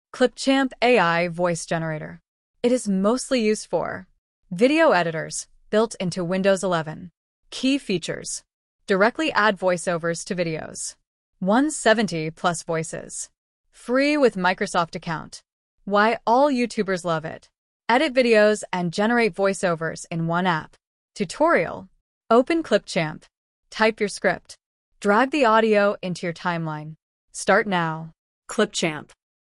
• Text to Speech Accuracy: It’s  AI ensures smooth pronunciation and natural intonation for high quality audio output.
Clipchamp-AI-Voice-Generator-Audio.mp3